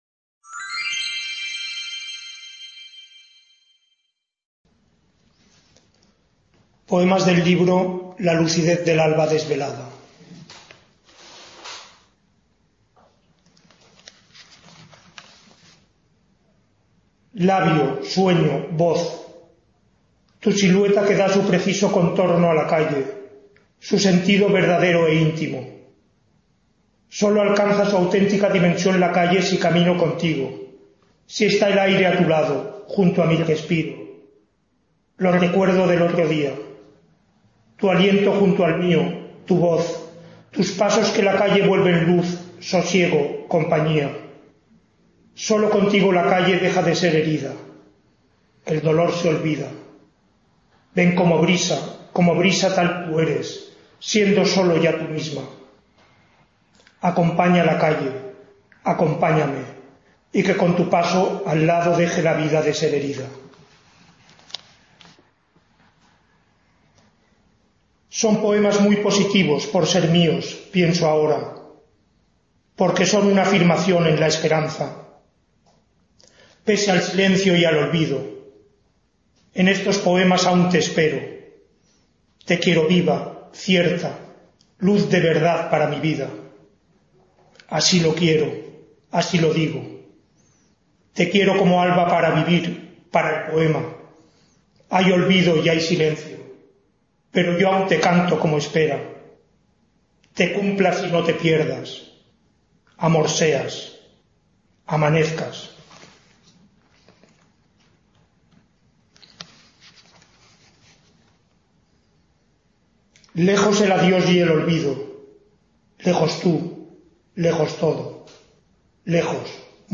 Lectura de poemas del libro La lucidez del alba…